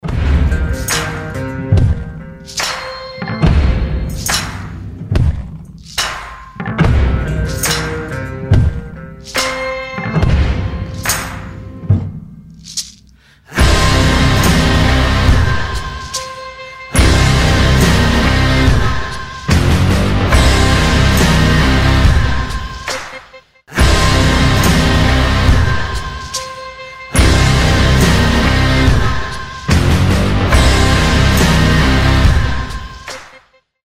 • Качество: 320, Stereo
громкие
крутые
без слов
Hard rock
heavy Metal